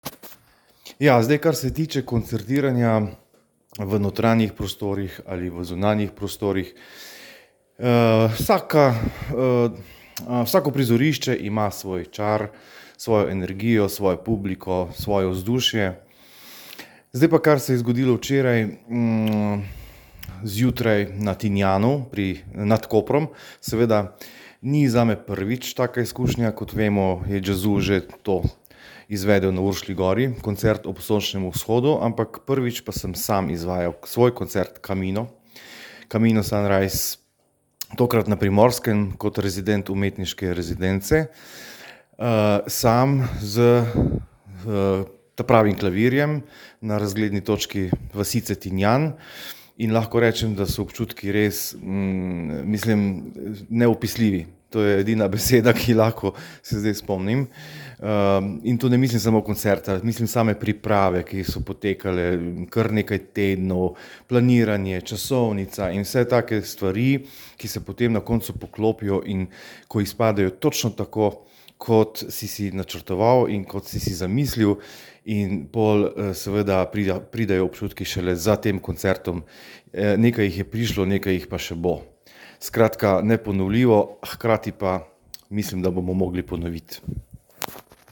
izjava po koncertu.mp3